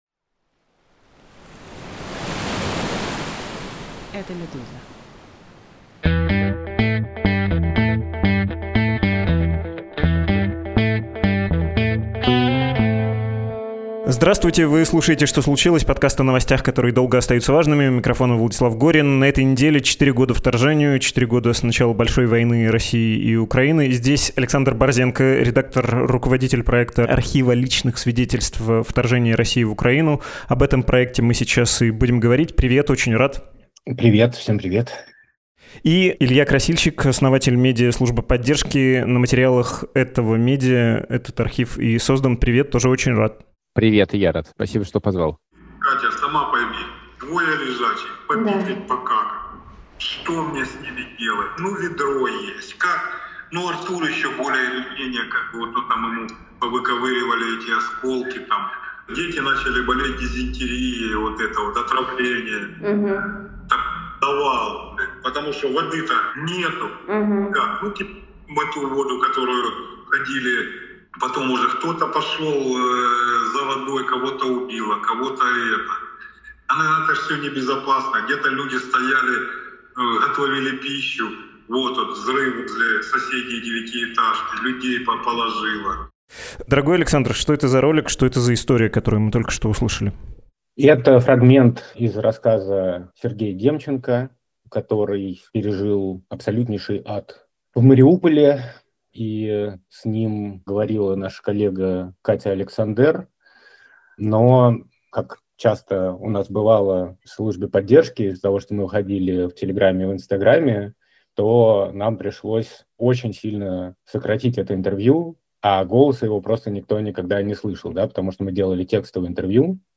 Слушаем голоса тех, кто пережил этот ужас